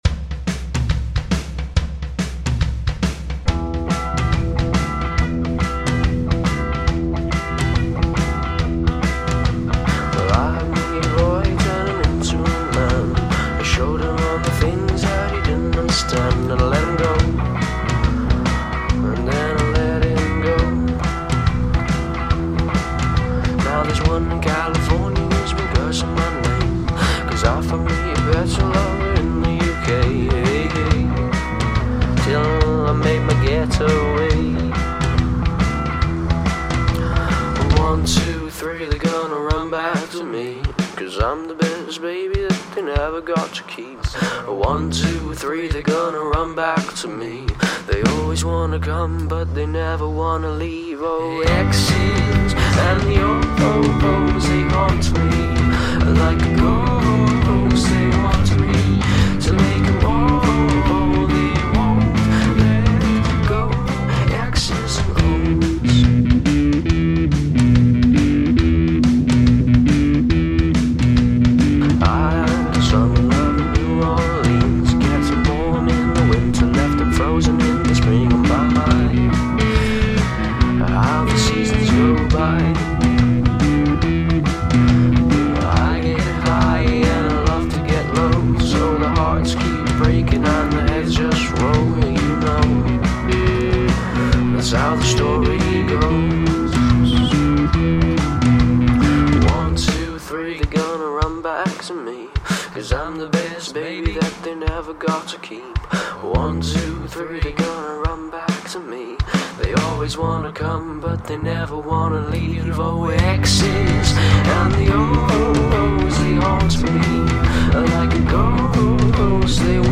Rock School Band 1
gtr
drums
bass.